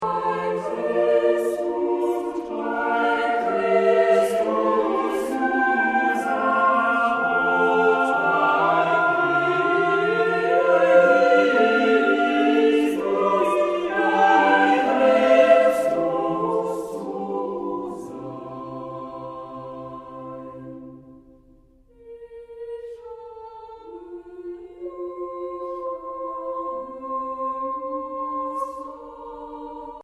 Eine Aufnahme überwiegend romantischer Chorliteratur
klangschönen und nuancenreichen Gesang